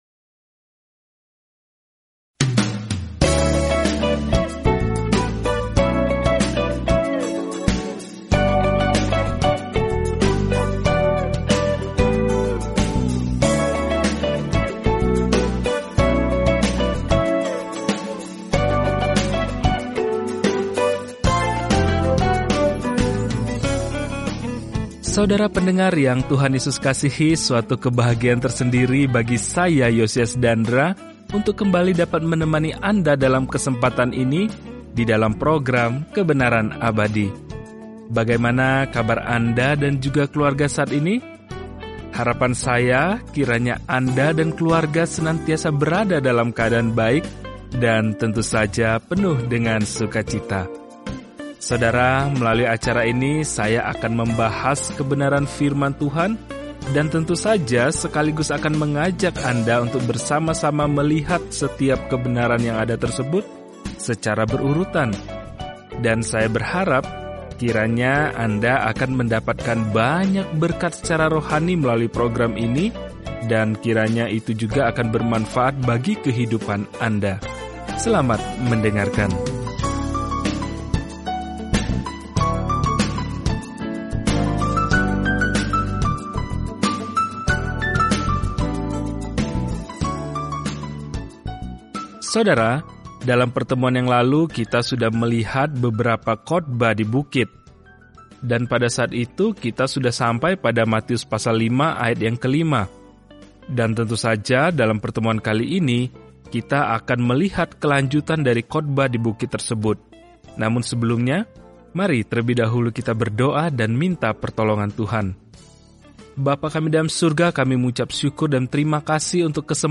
Firman Tuhan, Alkitab Matius 5:6-48 Hari 8 Mulai Rencana ini Hari 10 Tentang Rencana ini Matius membuktikan kepada para pembaca Yahudi kabar baik bahwa Yesus adalah Mesias mereka dengan menunjukkan bagaimana kehidupan dan pelayanan-Nya menggenapi nubuatan Perjanjian Lama. Telusuri Matius setiap hari sambil mendengarkan studi audio dan membaca ayat-ayat tertentu dari firman Tuhan.